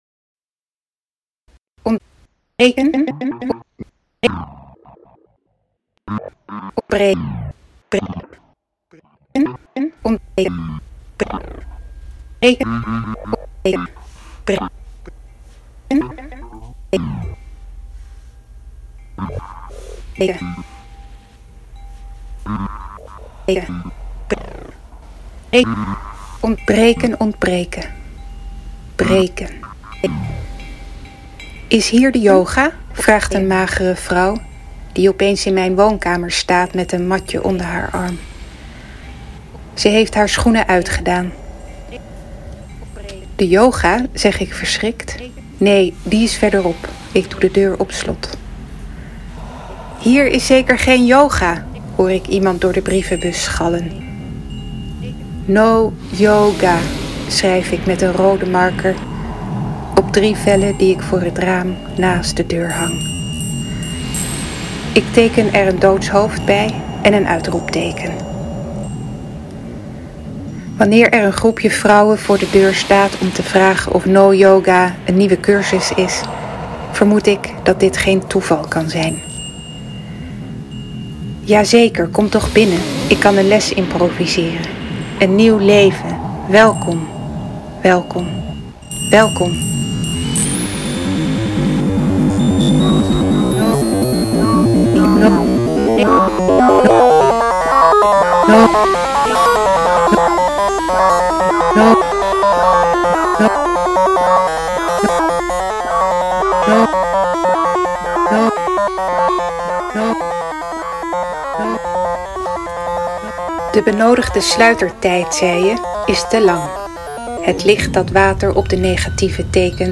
Het gedicht “Breken Ontbreken
met een soundscape